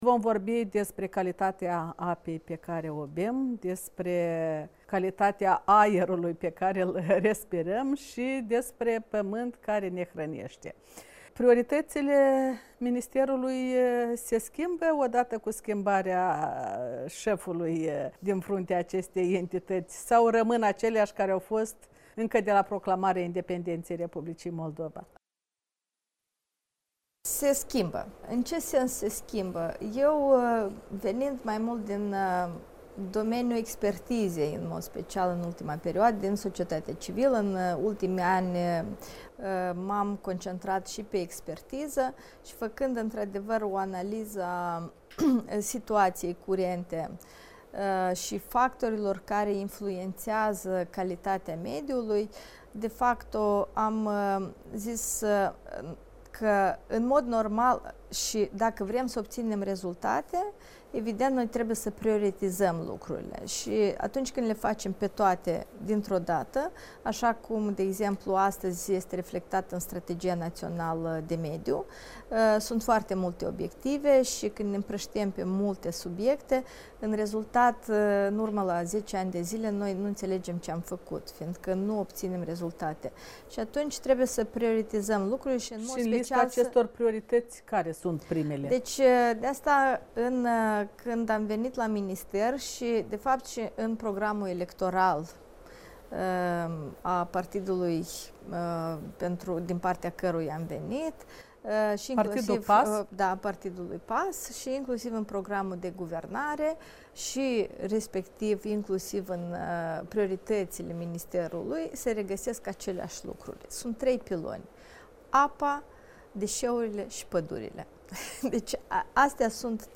Intervu cu ministrul mediului, Iuliana Cantaragiu